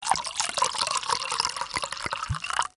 pourmilk2.ogg